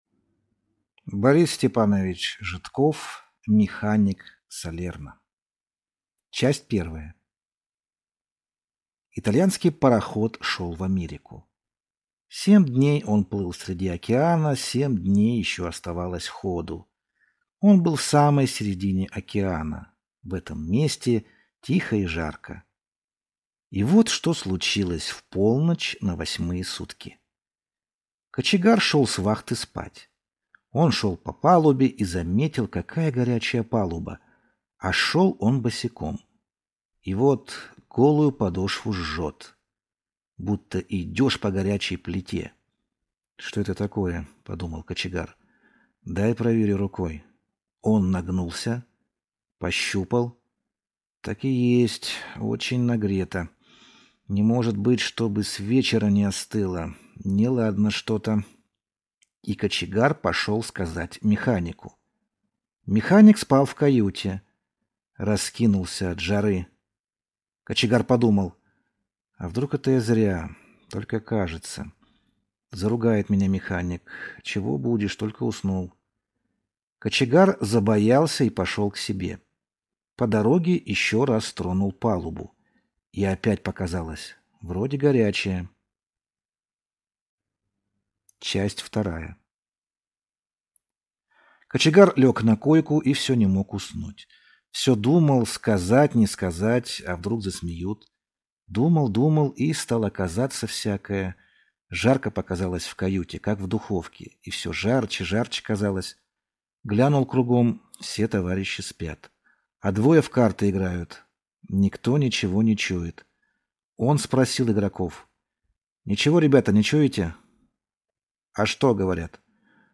Аудиокнига Механик Салерно | Библиотека аудиокниг